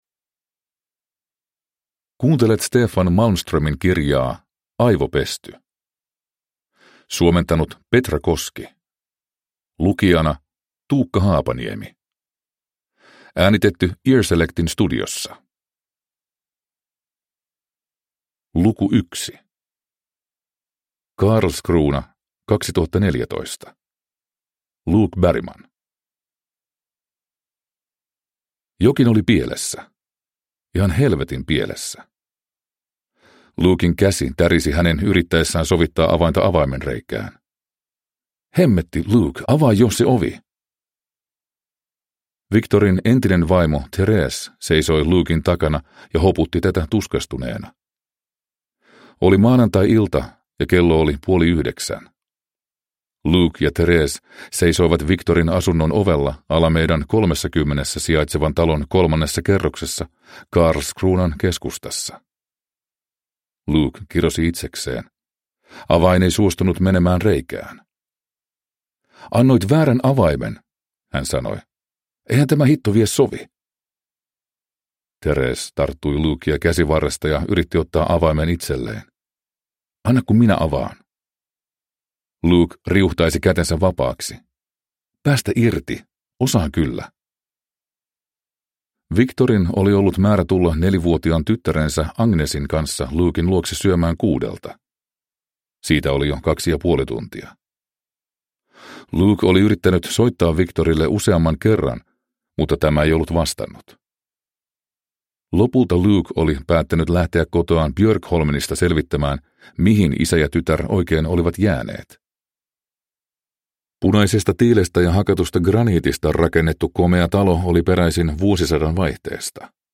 Aivopesty – Ljudbok
• Ljudbok